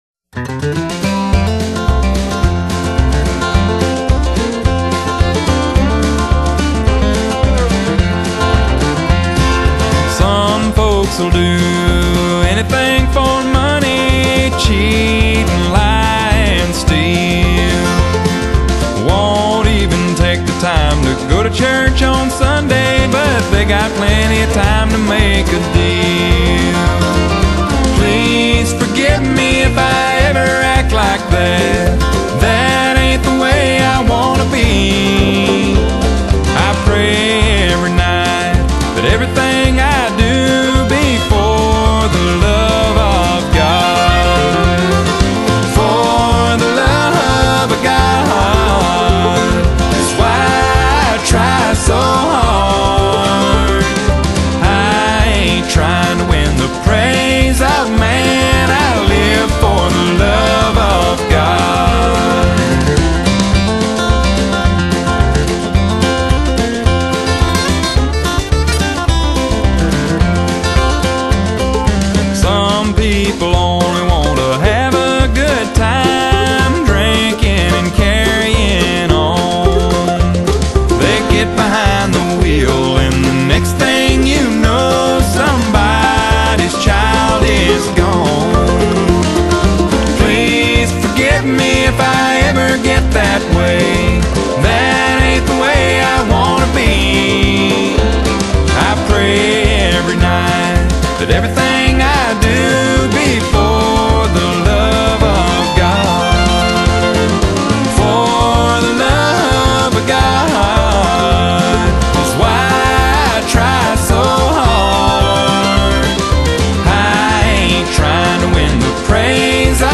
鄉村歌手